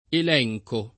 [ el $j ko ]